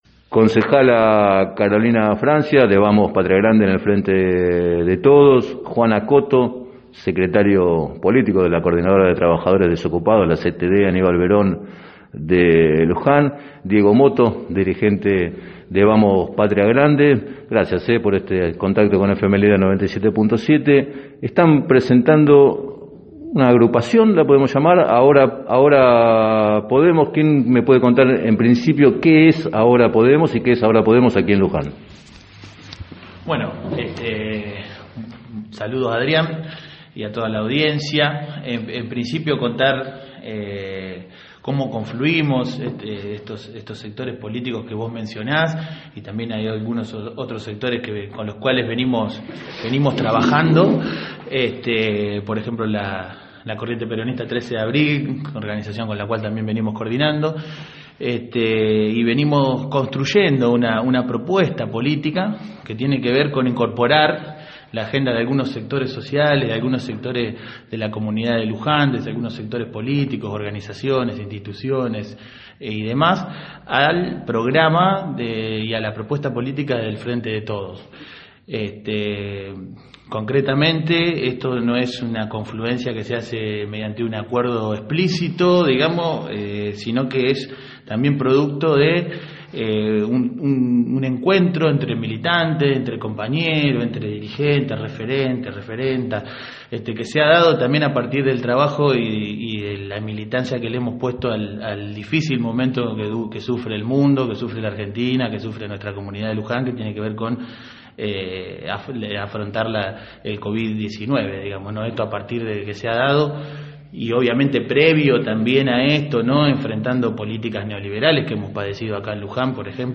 en declaraciones al programa Planeta Terri de FM Líder 97.7 explicaron que “Ahora Podemos” es producto del encuentro de experiencias de militancia durante la pandemia.